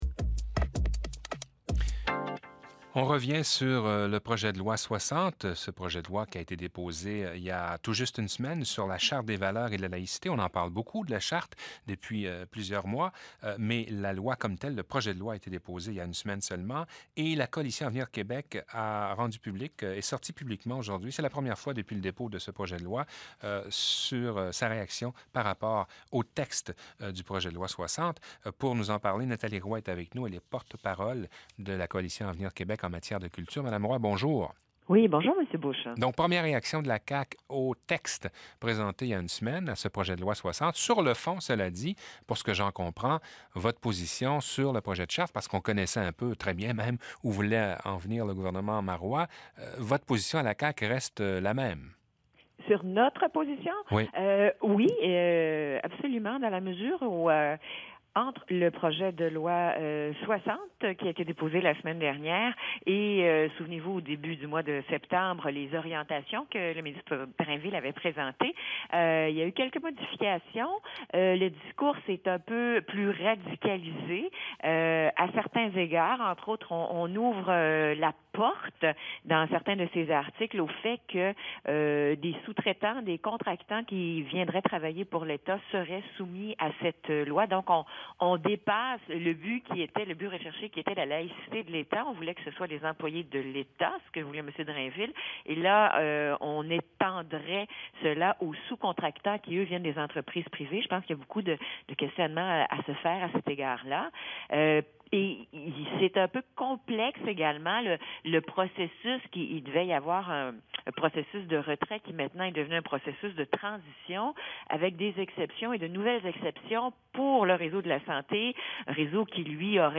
entrevue-radio-14-nov-2013.mp3